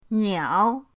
niao3.mp3